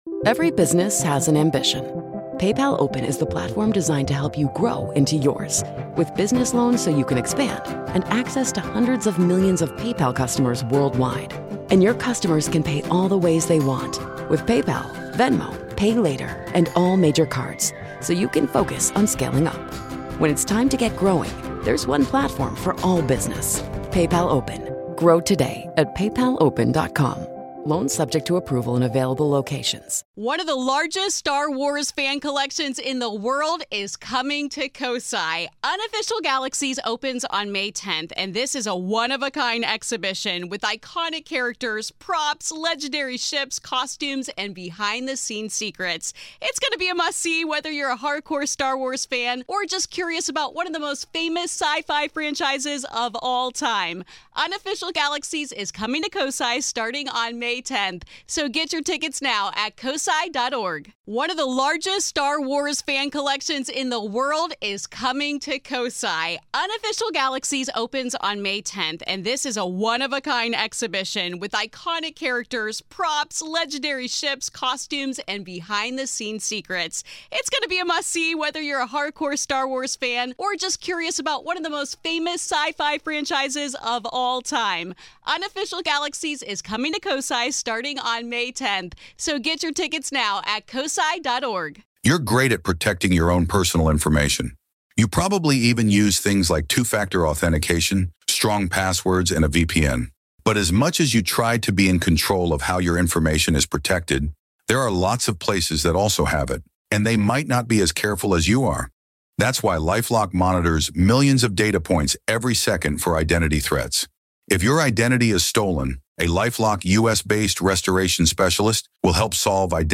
Today, on the Grave Talks, Part One of our conversation about The Gill House